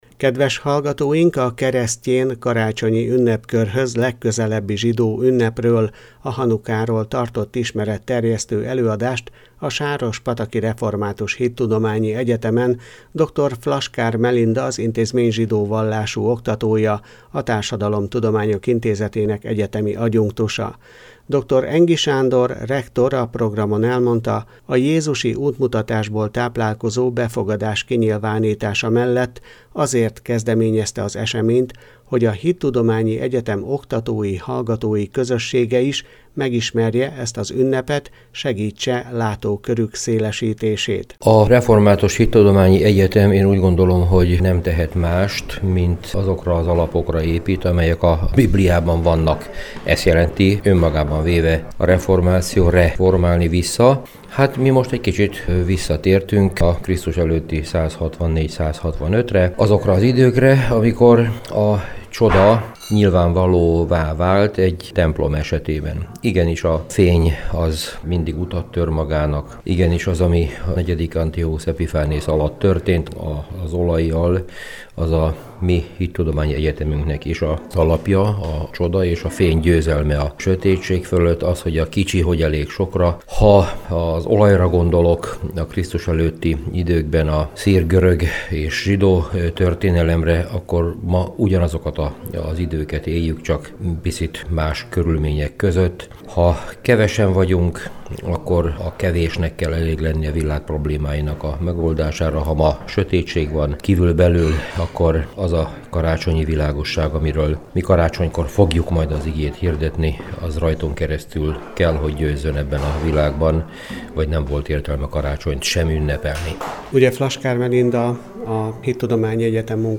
Ismeretterjesztő előadást tartottak a Hanukáról az SRHE-n